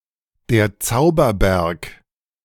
The Magic Mountain (German: Der Zauberberg, pronounced [deːɐ̯ ˈt͡saʊ̯bɐˌbɛʁk]